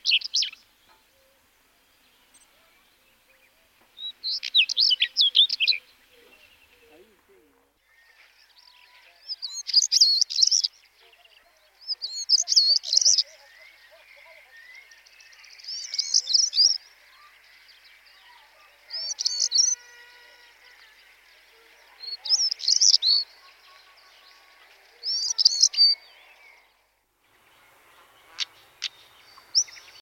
Photos de Tarier pâtre - Mes Zoazos
tarier-patre-2.mp3